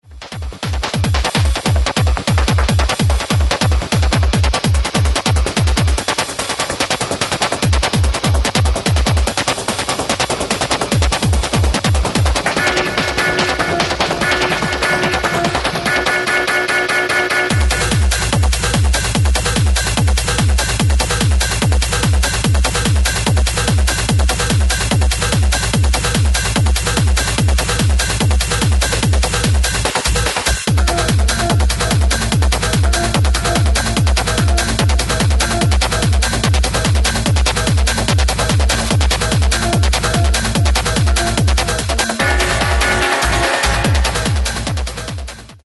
Styl: Techno, Hardtek/Hardcore